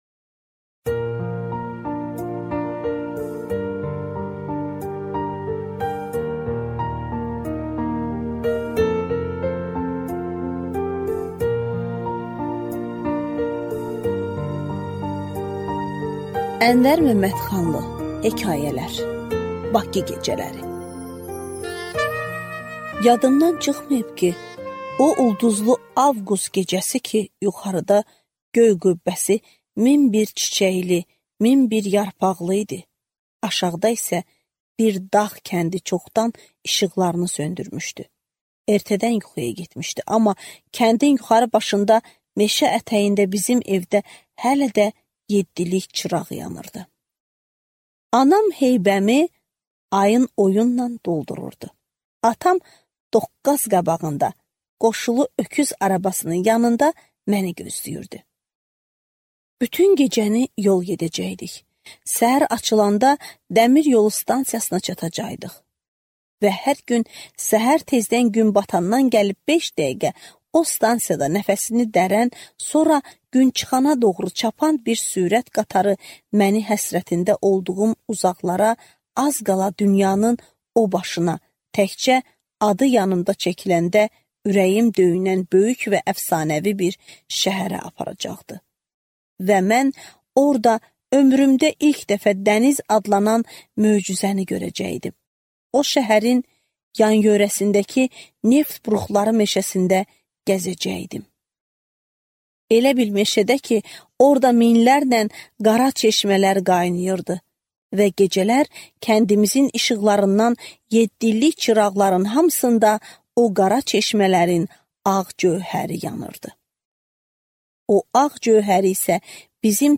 Аудиокнига Hekayələr | Библиотека аудиокниг